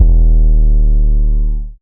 DDW6 808 4.wav